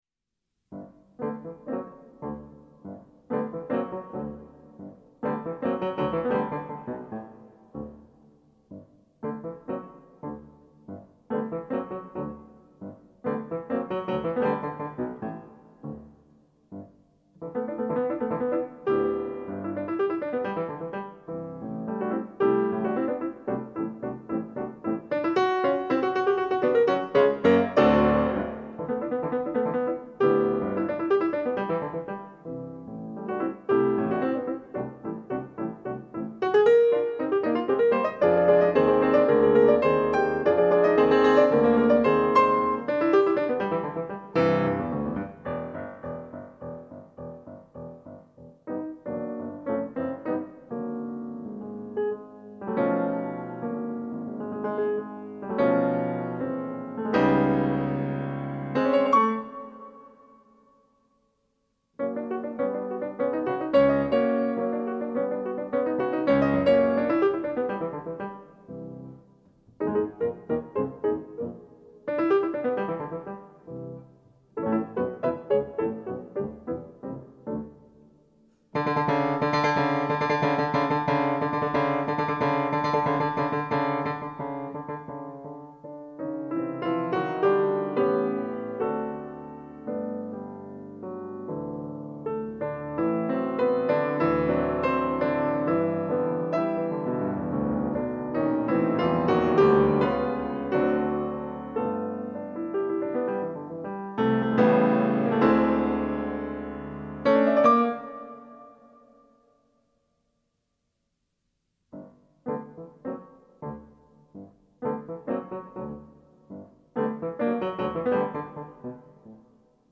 Audio-Aufnahmen (Studio):
Prelúde XII / Book 1 "Minstrels" (C. Debussy) - ORF Landesstudio Stmk.